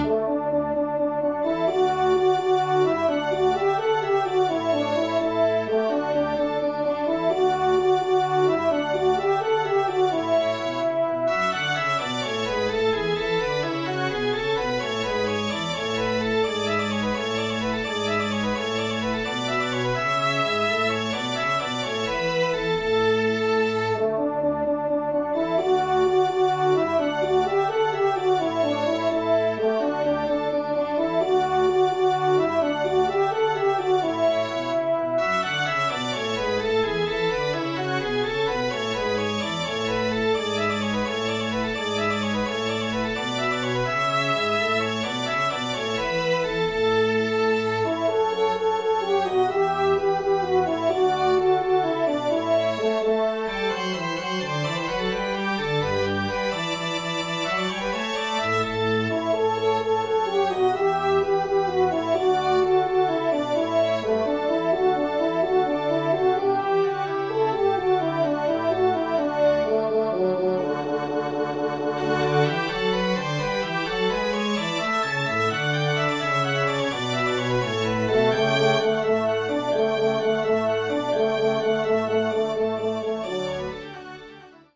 ENSEMBLE (Allegro complet)